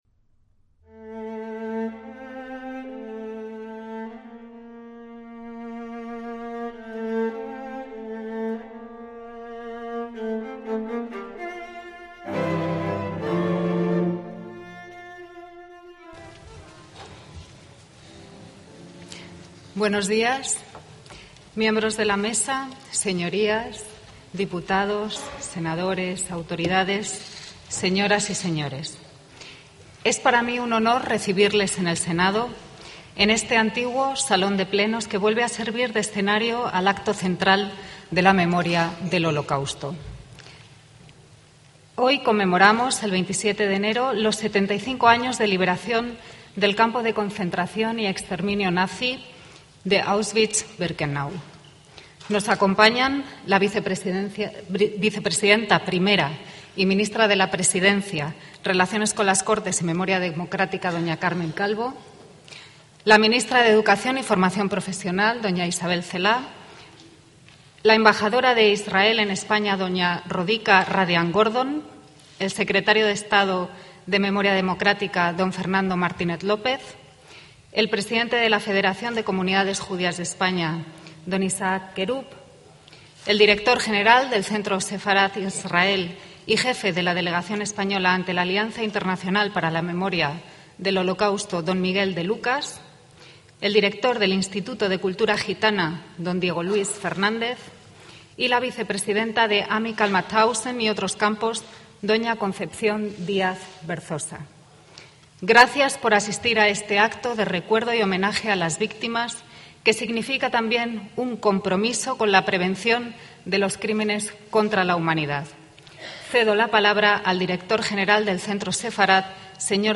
ACTOS EN DIRECTO - El 27 de enero de 2020, Día Oficial de la memoria del Holocausto y la prevención de los crímenes contra la humanidad, tuvo lugar en el Antiguo Salón de Sesiones del Senado español el acto de estado en conmemoración del 75º aniversario de la liberación del campo de exterminio de Auschwitz.